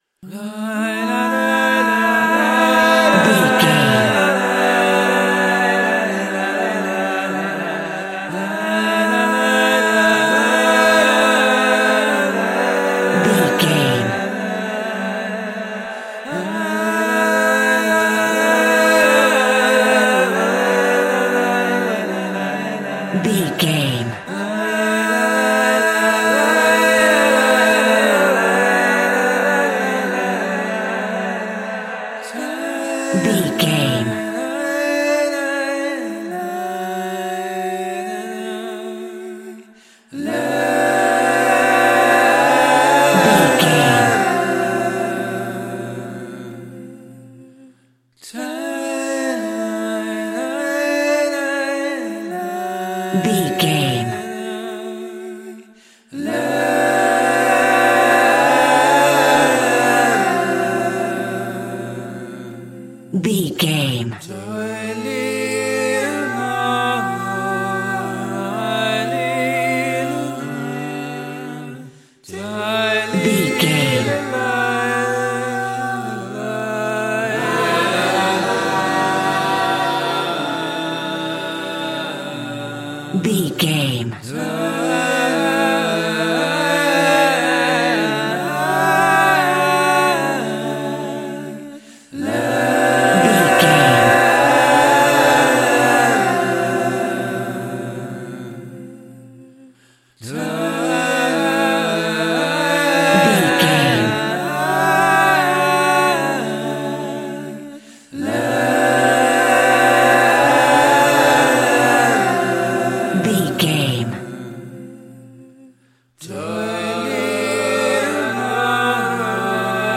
Aeolian/Minor
groovy
inspirational